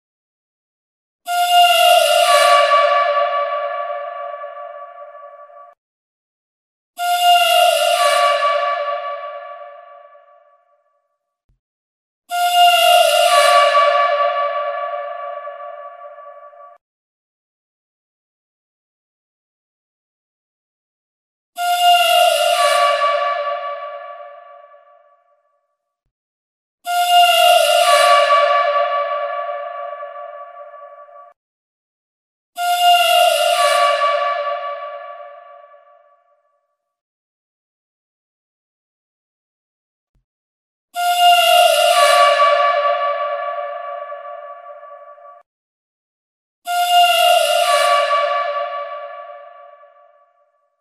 Genre: เสียงเรียกเข้าติ๊กต๊อก